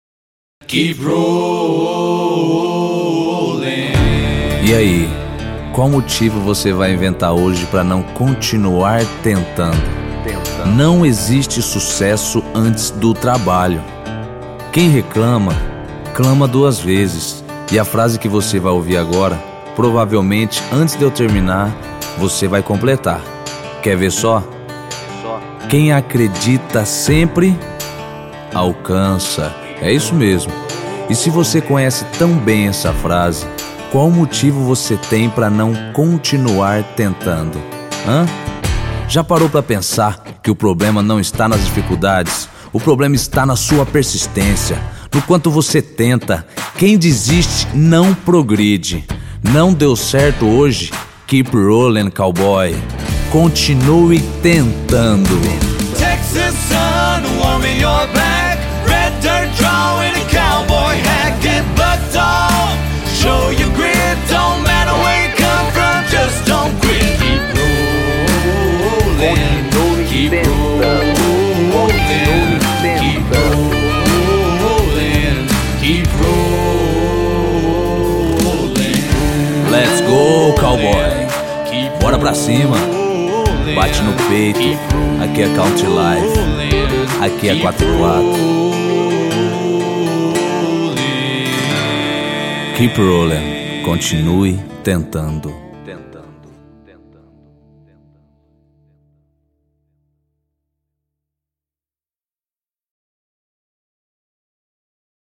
EstiloCountry